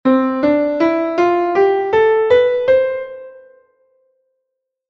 That same C major scale can be played on the piano 🎹:
c-major-scale.mp3